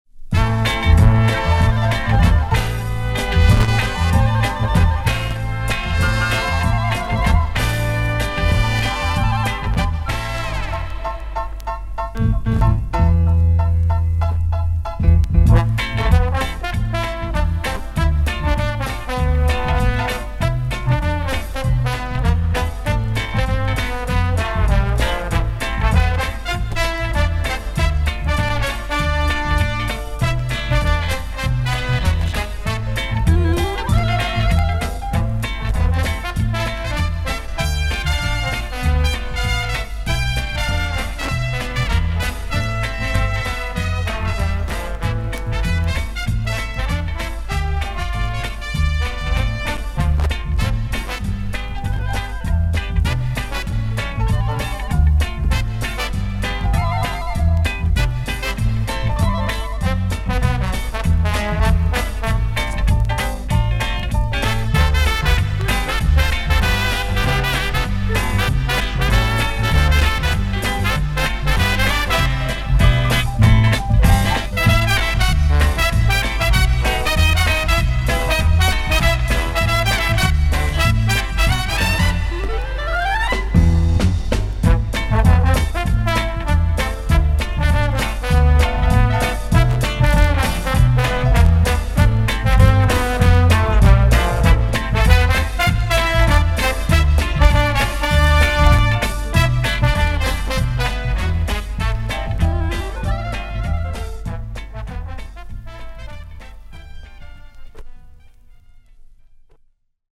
ансамбль